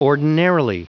Prononciation du mot ordinarily en anglais (fichier audio)
Prononciation du mot : ordinarily